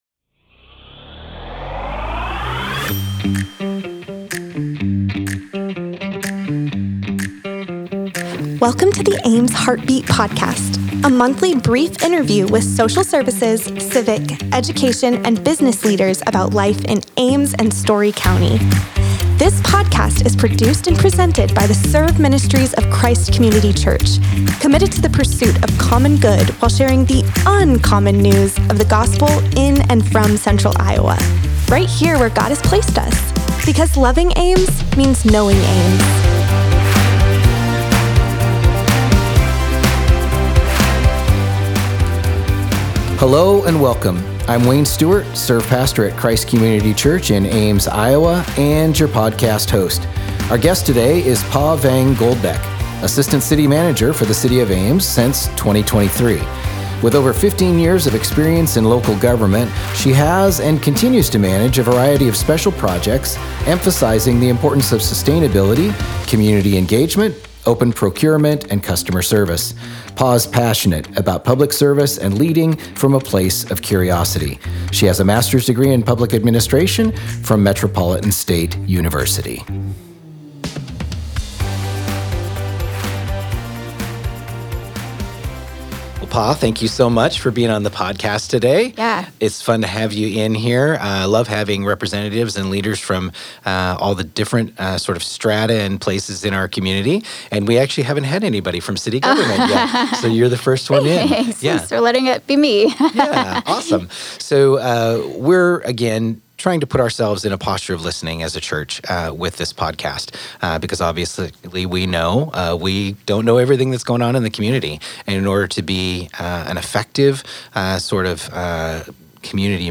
The Ames Heartbeat Podcast features brief interviews with civic, education, social services, and business leaders in Ames and surrounding communities. This episode's guest is Pa Vang Goldbeck, Assistant City Manager for the City of Ames.